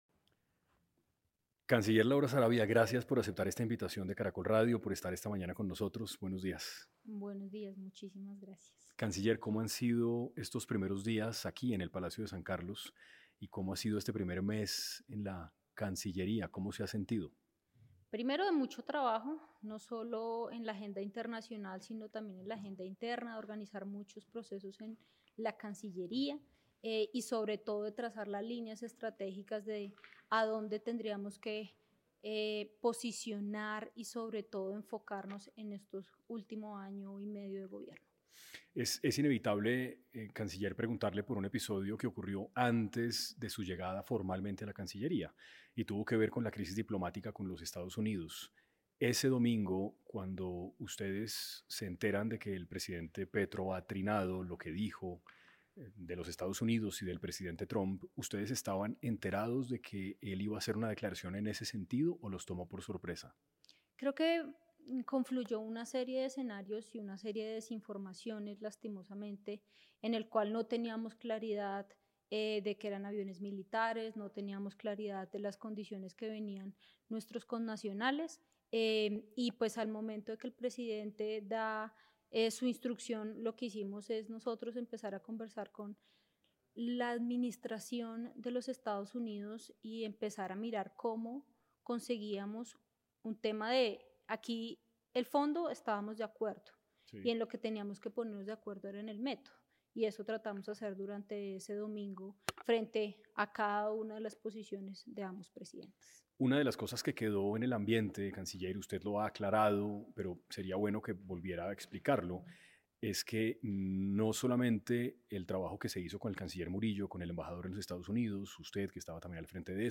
Laura Sarabia en entrevista con 6AM habló sobre sus retos en la Cancillería, la investigación contra Benedetti, algunas polémicas que le ha generado su valor en el gobierno y otros temas.